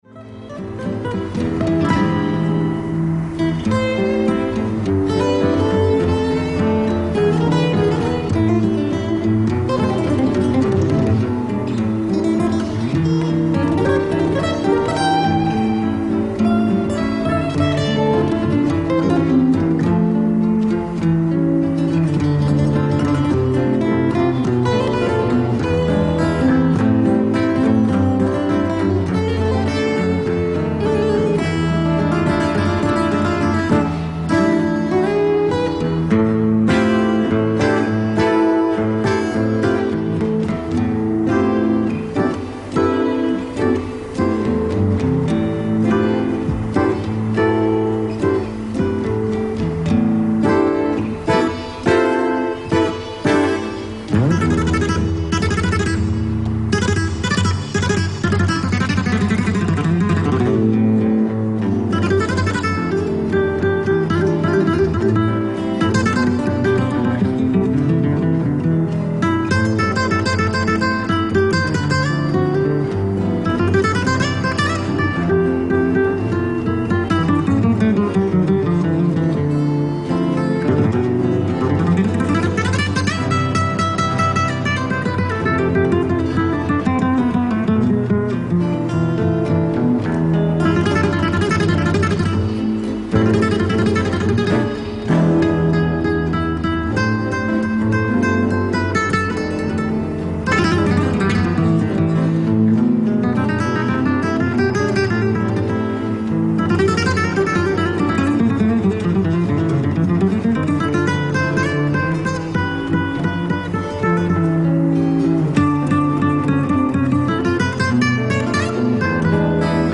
ライブ・アット・ロイヤル・アルバートホール、ロンドン 02/14/1979
※試聴用に実際より音質を落としています。